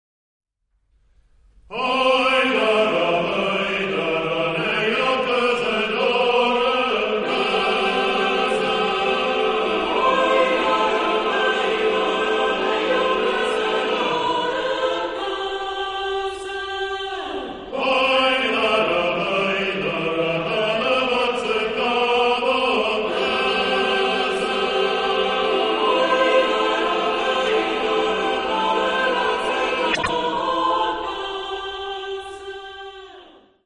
Drei Arbeitslieder aus Setu
Genre-Stil-Form: weltlich ; Volkstümlich
Charakter des Stückes: schnell ; fröhlich ; lebhaft
Chorgattung: SATB  (4 gemischter Chor Stimmen )
Tonart(en): C-Dur